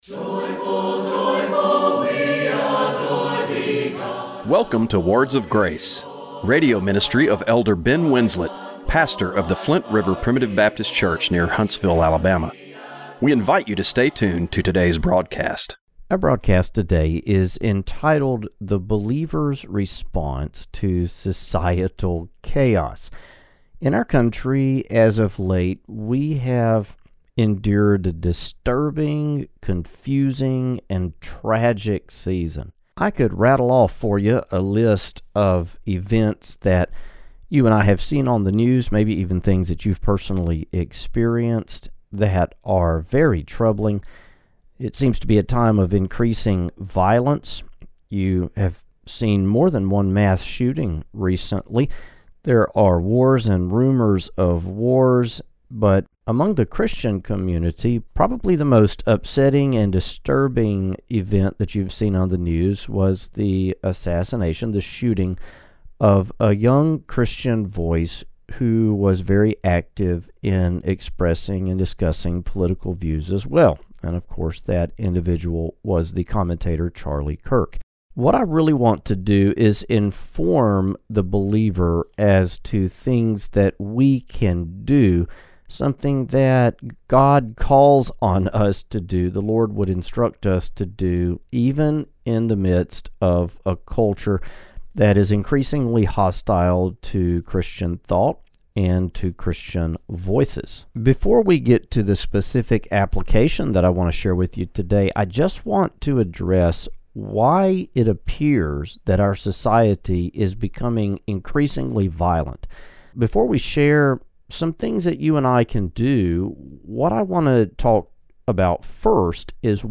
Radio broadcast for September 21, 2025.